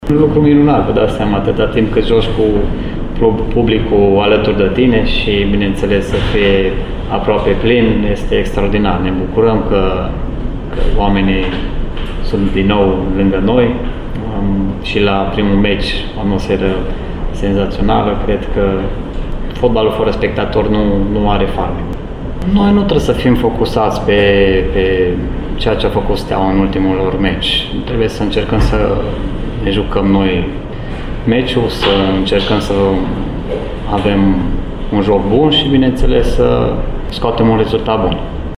Tot la acest final de săptămână, Radio Timișoara transmite partida UTA – FCSB, din etapa a III-a a Ligii I de fotbal, meci ce începe la ora 22,00. Jucătorul arădenilor, Liviu Antal, este încântat că biletele la acest meci s-au vândut în timp record: